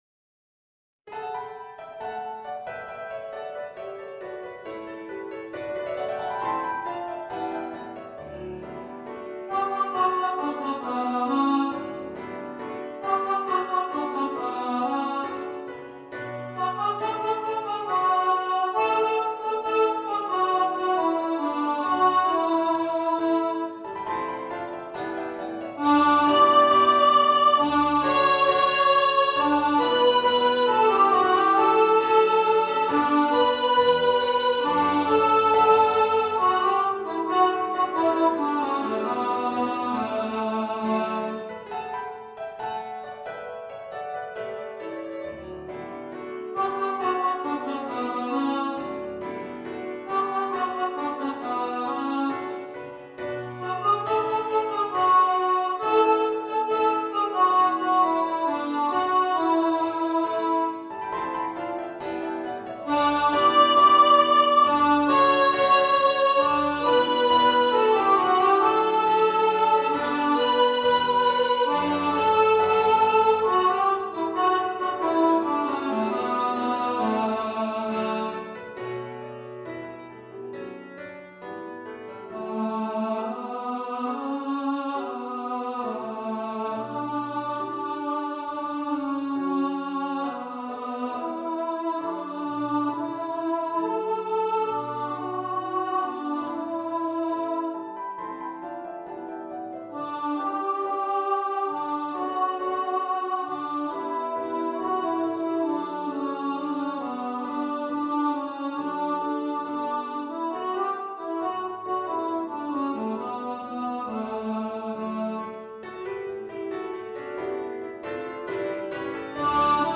Star-Carol-Alto.mp3